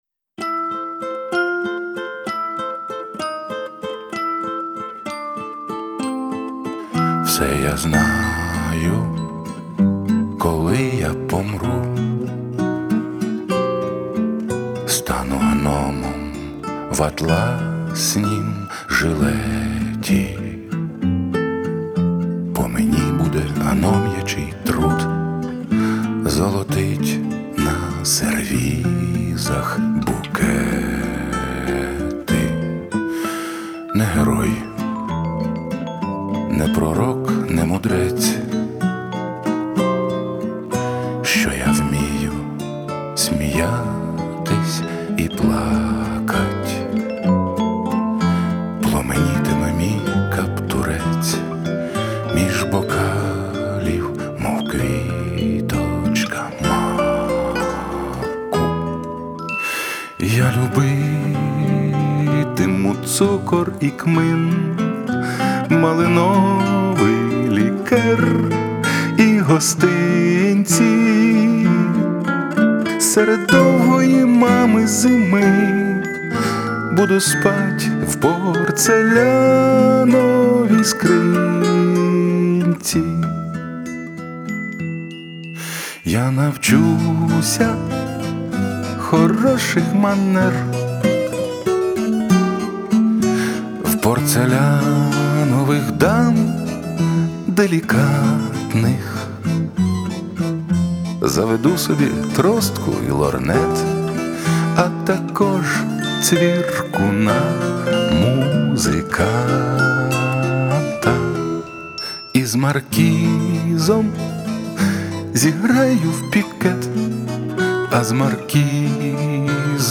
пісня